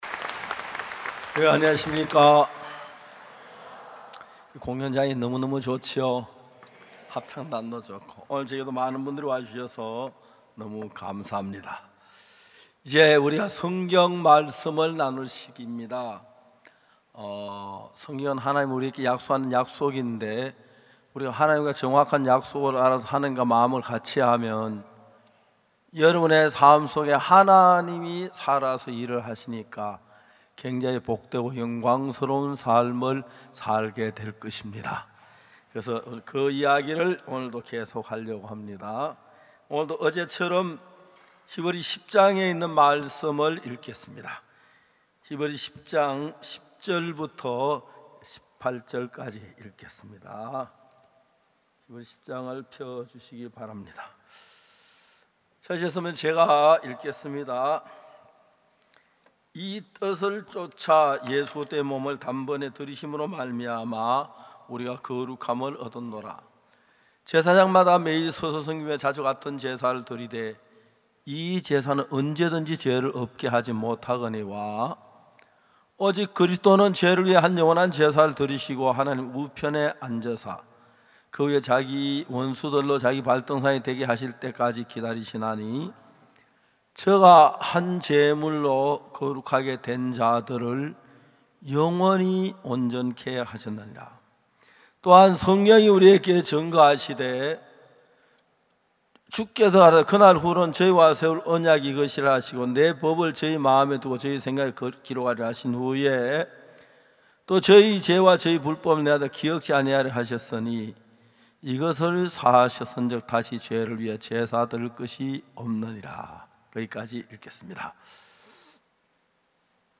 성경세미나 설교를 굿뉴스티비를 통해 보실 수 있습니다.